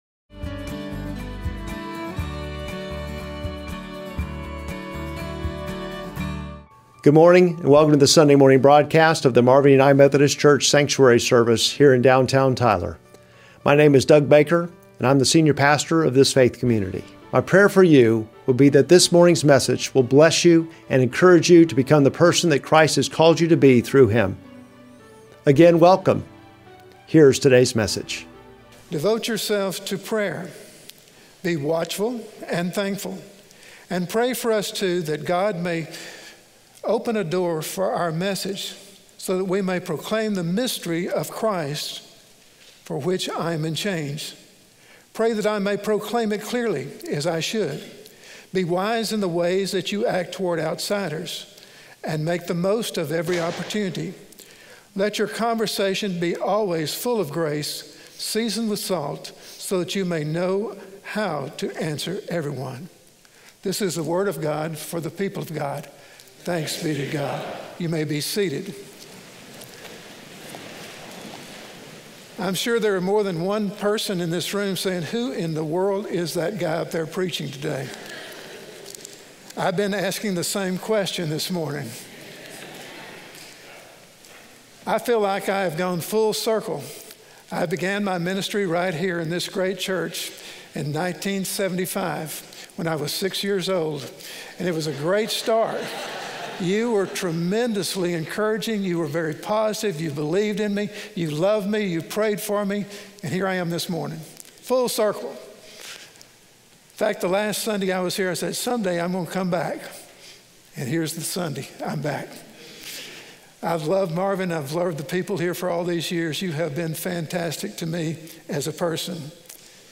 Sermon text: Colossians 4:2-9